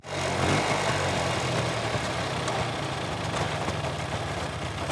Engine 1 Start.wav